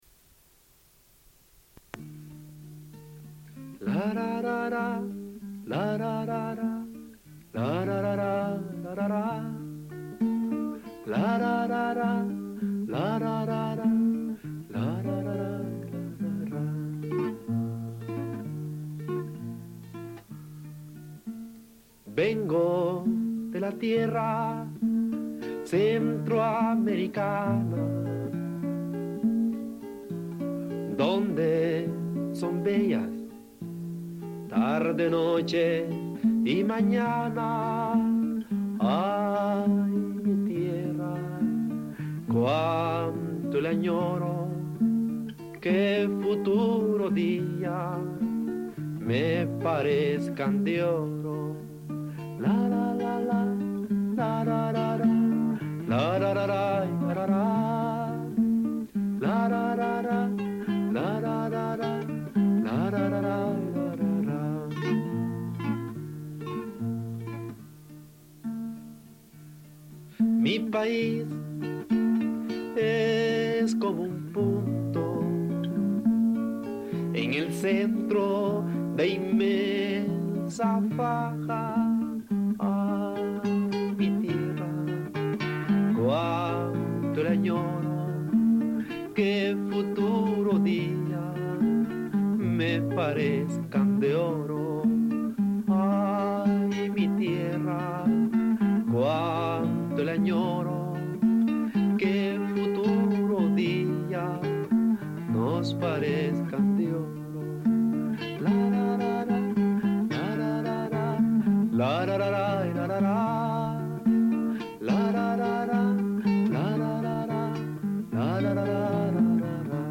Une cassette audio, face A45:22